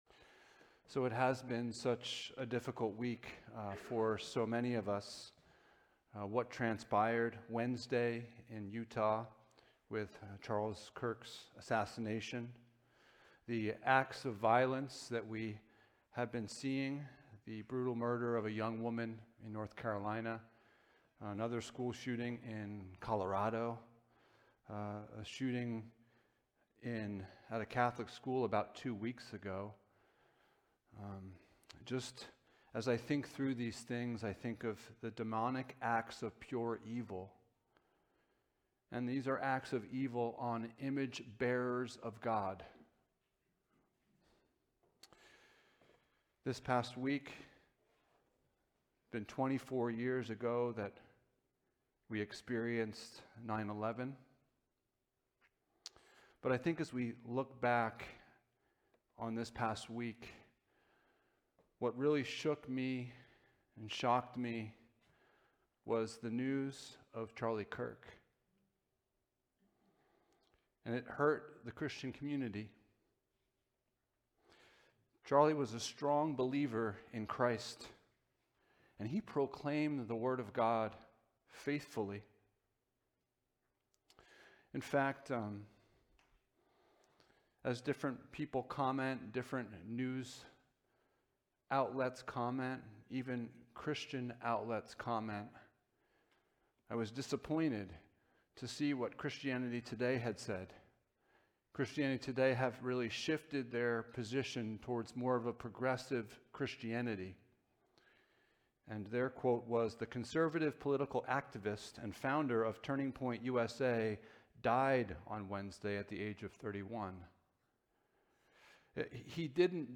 8-14 Service Type: Sunday Morning A response to the murder of Charlie Kirk.